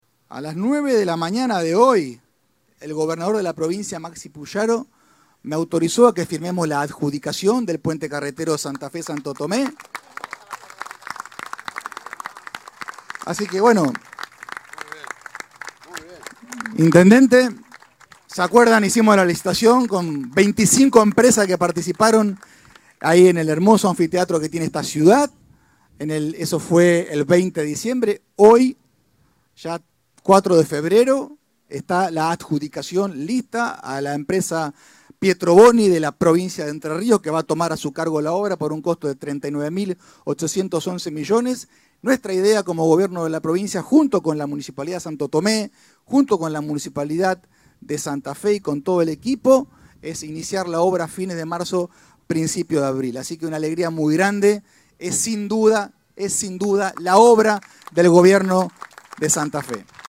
Lisandro Enrico, ministro de Obras Públicas